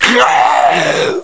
assets/ctr/nzportable/nzp/sounds/zombie/d4.wav at dcbec5b3105fff11b1dd9217da0e5c3faa377fb6
dcbec5b310 assets / ctr / nzportable / nzp / sounds / zombie / d4.wav Steam Deck User 08712ab263 PSP/CTR: Also make weapon and zombie sounds 8bit 2023-02-20 17:40:04 -05:00 20 KiB Raw History